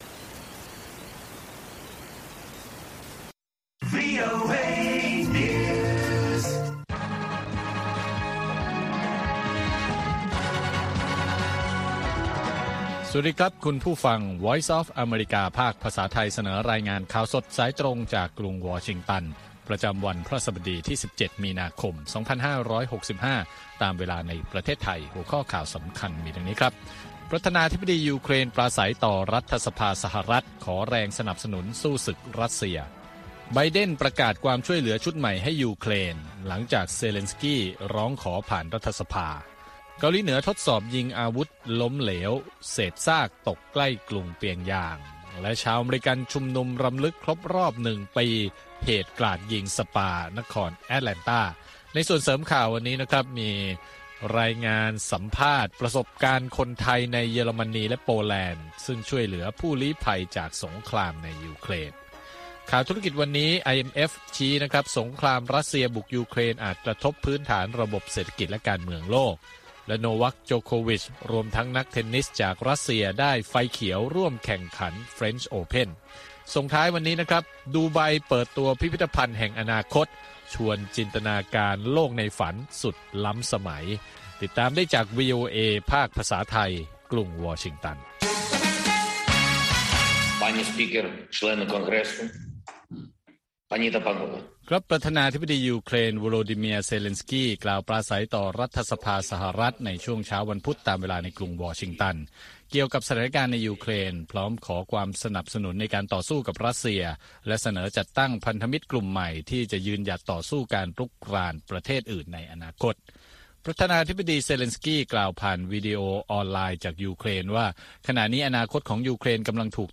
ข่าวสดสายตรงจากวีโอเอ ภาคภาษาไทย 8:30–9:00 น. ประจำวันพฤหัสบดีที่ 17 มีนาคม 2565 ตามเวลาในประเทศไทย